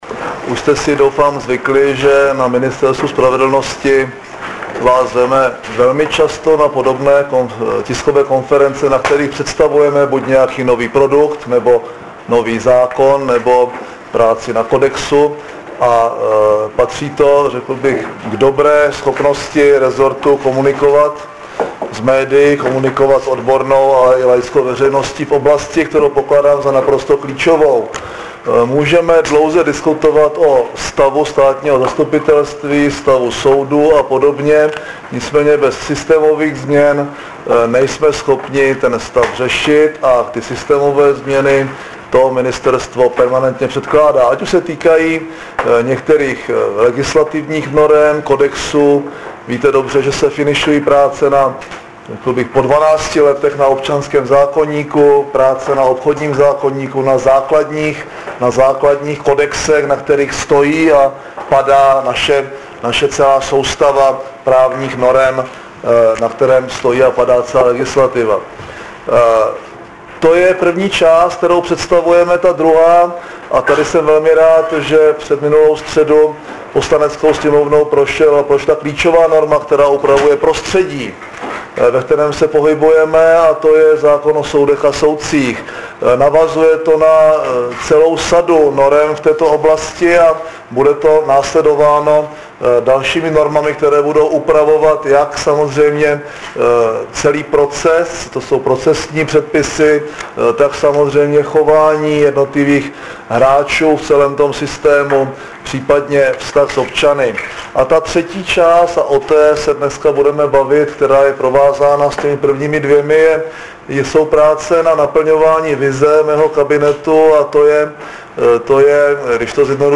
Nabízíme zvukový záznam vystoupení premiéra M. Topolánka na dnešní tiskové konferenci.
Zvukový záznam vystoupení premiéra na tiskové konferenci u příleľitosti představení nového institutu